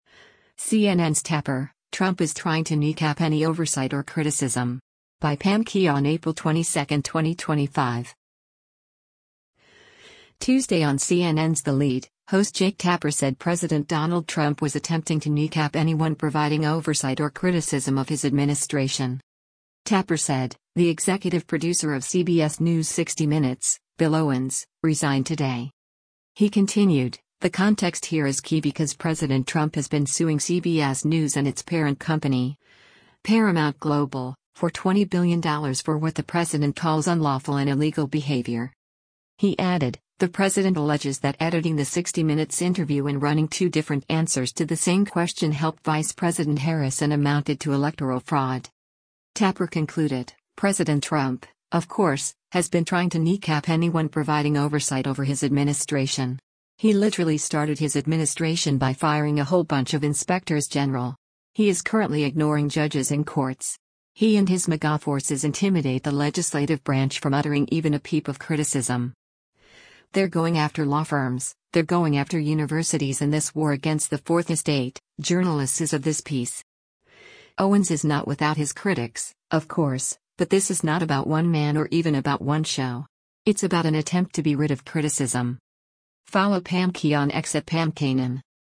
Tuesday on CNN’s “The Lead,” host Jake Tapper said President Donald Trump was attempting to “kneecap” anyone providing oversight or criticism of his administration.